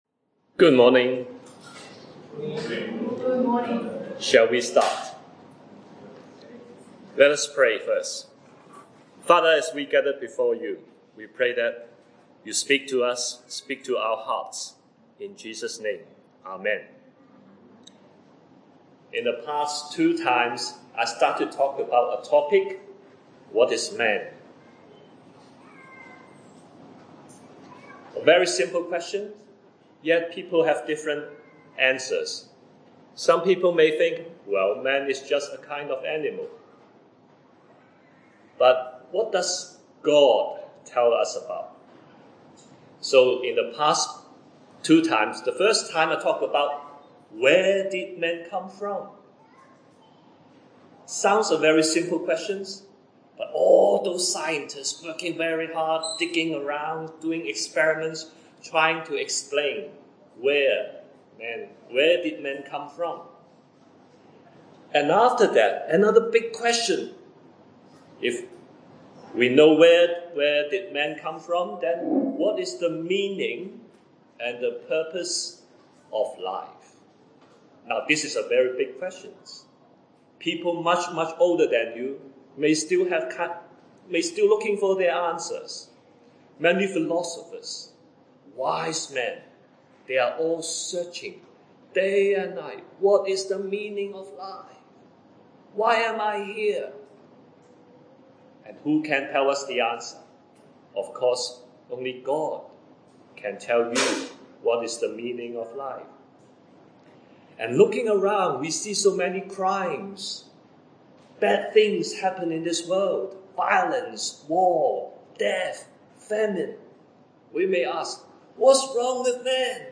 Matthew 28:19-20 A brief talk about how to receive new life in Christ and the expression of our faith and obedience through baptism.